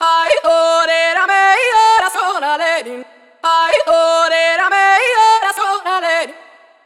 Original Vocal
KSHMR_Vocal_Melody_38_140_Em.wav